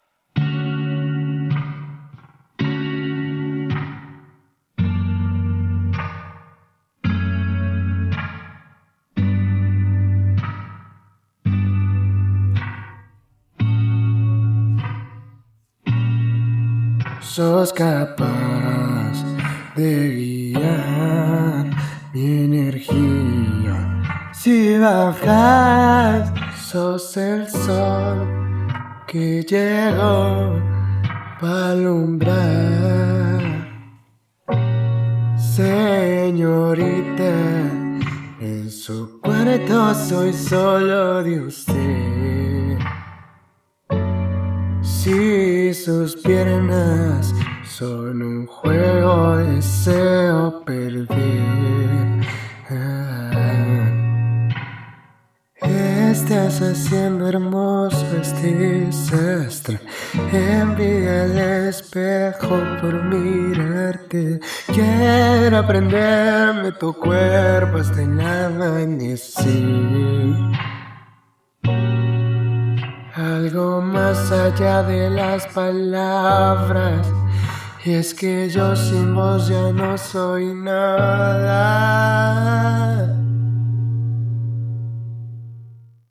Me pareció que te pfalta un poco de afinación.